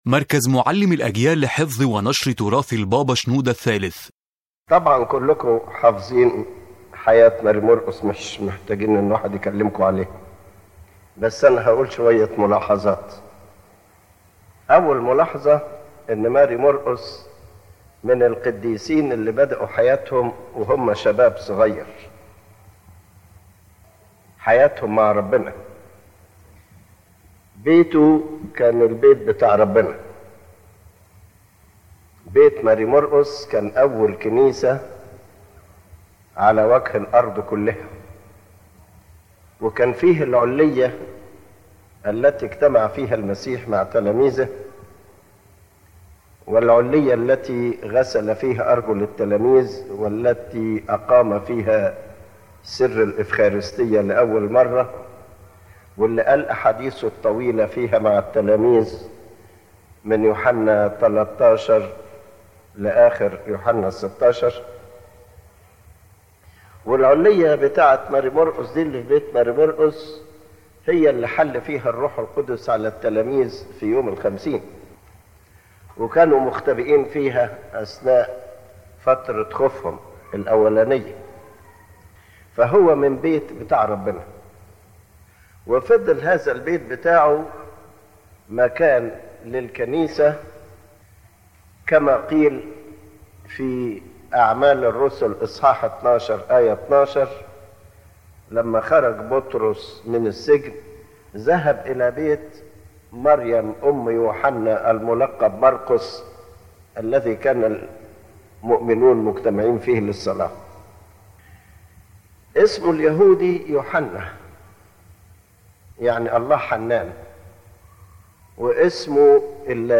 Main Idea: The lecture discusses the life of Saint Mark the Apostle as a great preacher, and his role in establishing the early Church and spreading the Christian faith with strength and effectiveness from his youth.